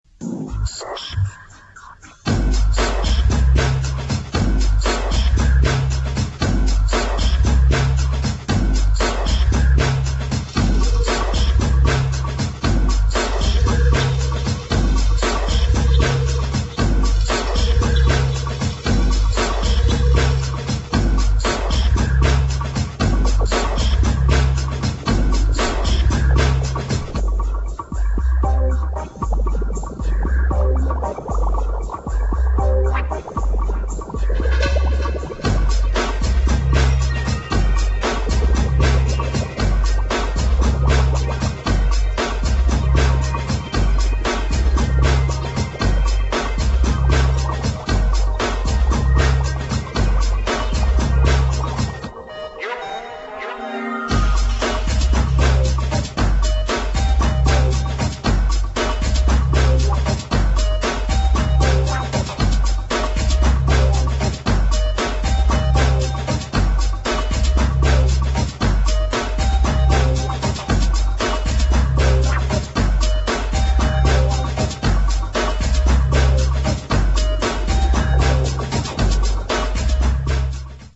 [ BREAKBEAT | DOWNBEAT ]